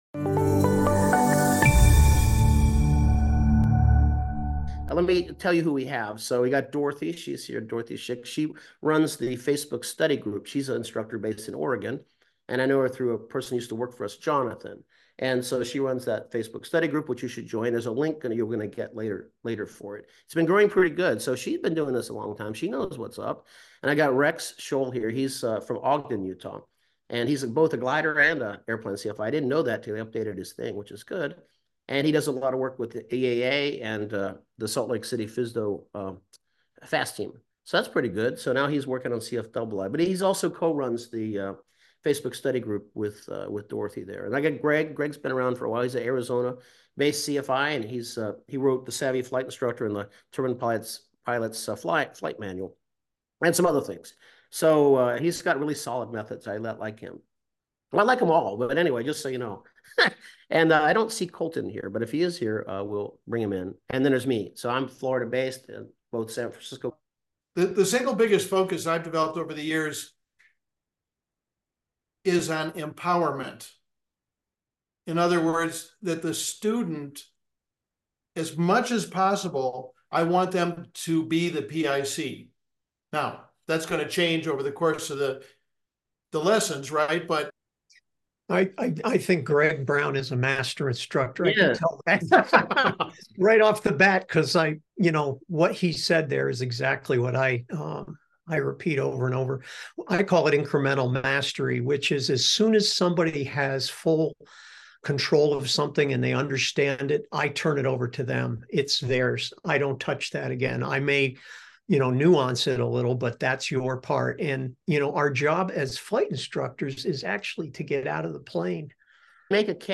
This Free Training provides a Live Discussion with Industry Leading CFI's and their experiences with students.